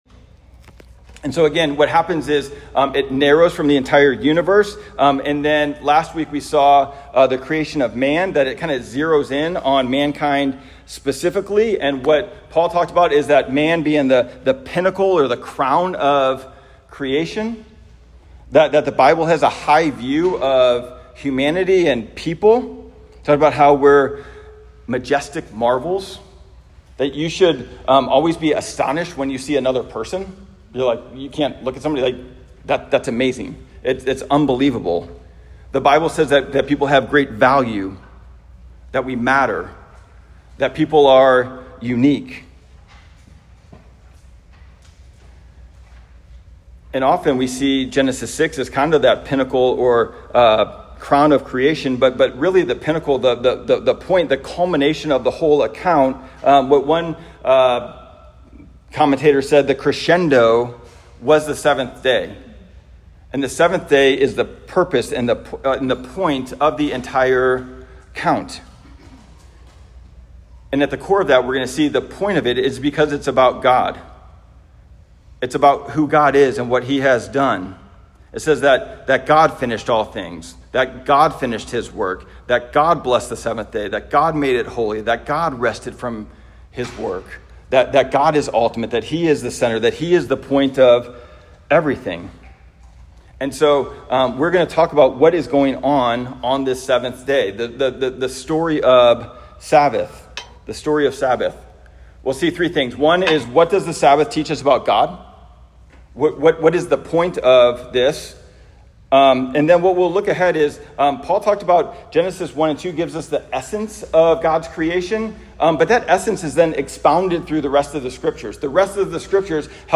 Genesis-2.1-3_Sermon-Only.m4a